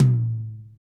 TOM RLTOM1BL.wav